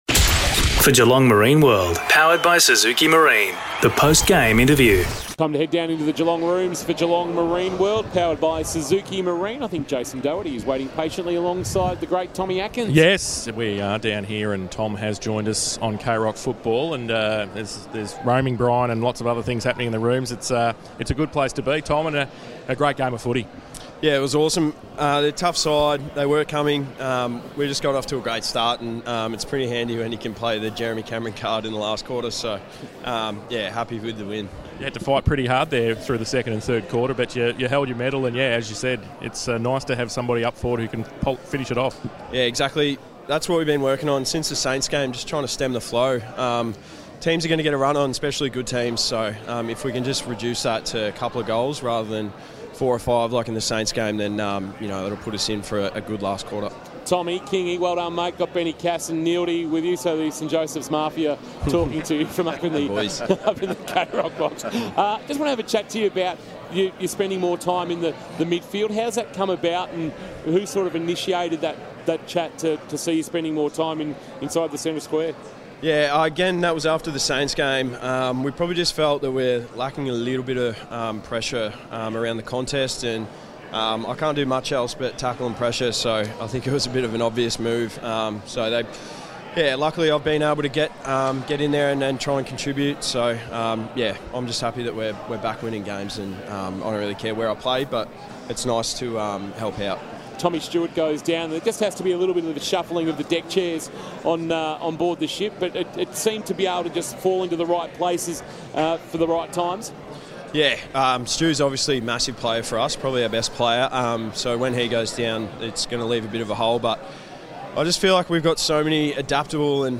2022 - AFL ROUND 12 - WESTERN BULLDOGS vs. GEELONG: Post-match Interview - Tom Atkins (Geelong)